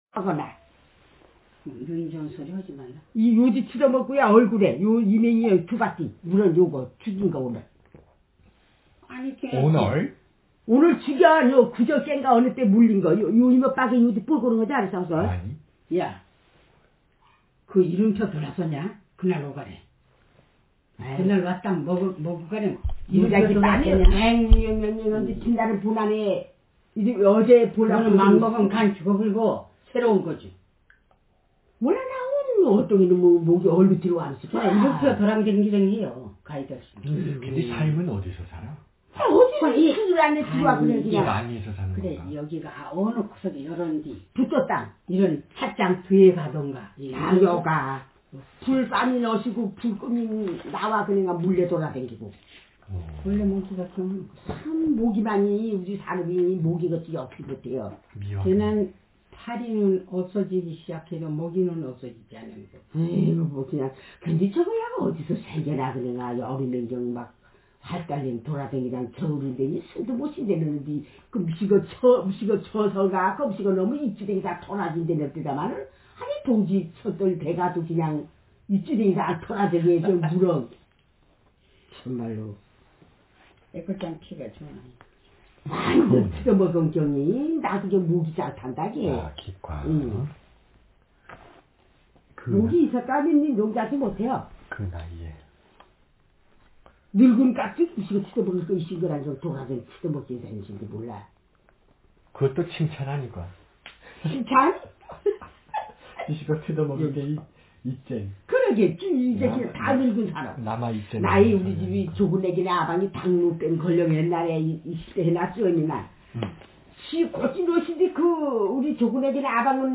Speaker sexf
Text genreconversation